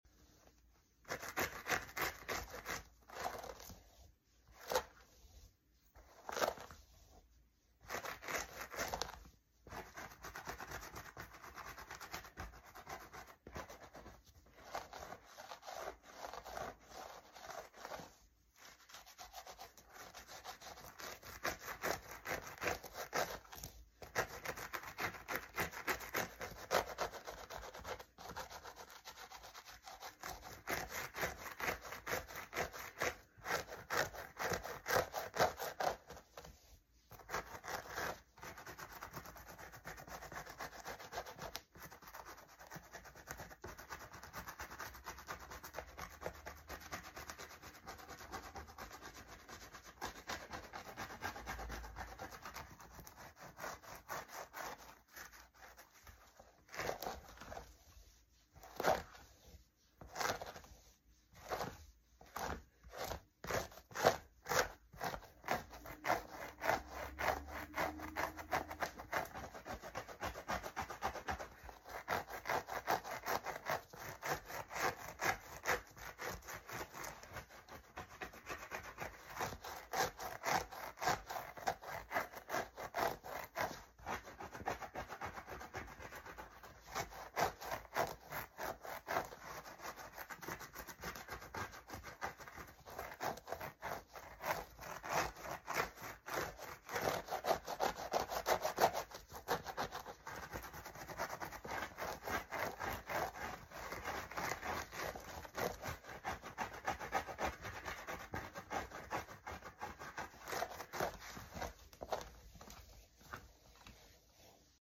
ASMR | Scratching in between sound effects free download
ASMR | Scratching in between braids